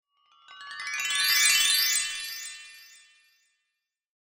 shimmer.mp3